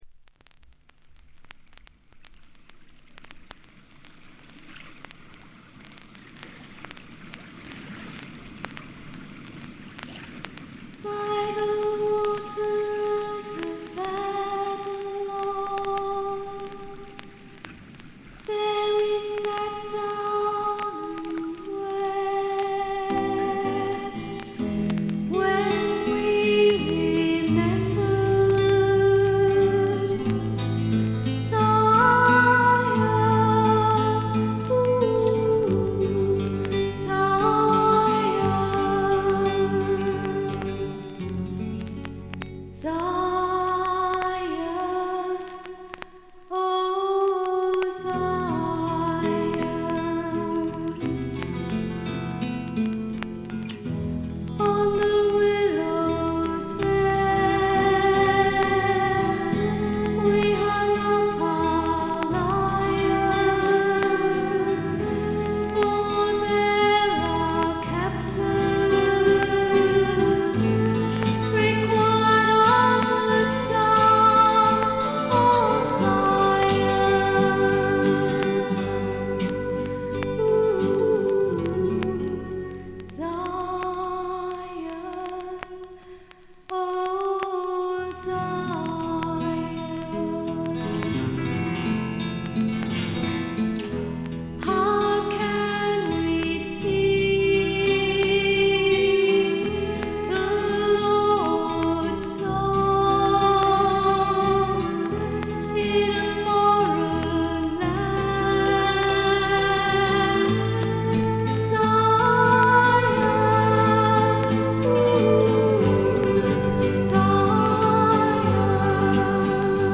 バックグラウンドノイズ、チリプチ多め大きめ。B3周回ノイズ。
妖精フィメール入り英国ミスティック・フォーク、ドリーミー・フォークの傑作です。
霧深い深山幽谷から静かに流れてくるような神秘的な歌の数々。
それにしても録音の悪さが幸いしてるのか（？）、この神秘感は半端ないです。
試聴曲は現品からの取り込み音源です。